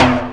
prcTTE44014tom.wav